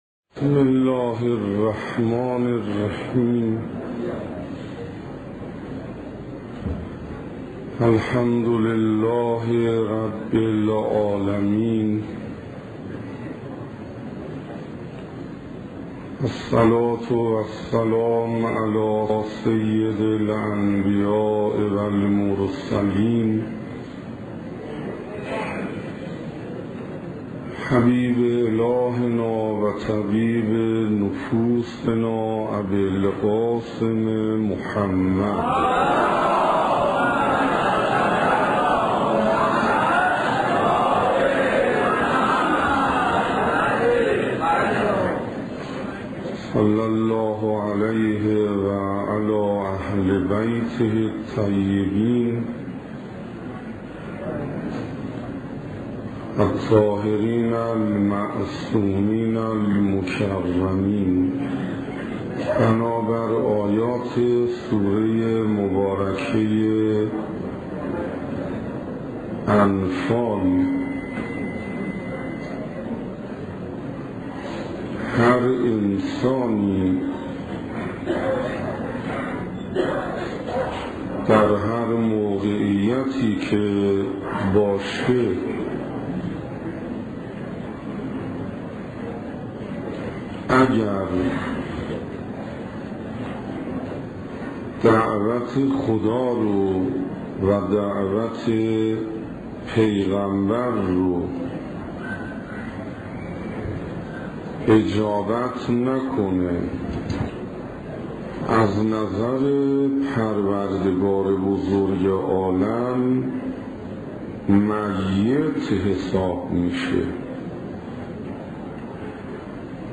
سخنرانی حجت الاسلام والمسلمین انصاریان با موضوع معارف امام رضا علیه السلام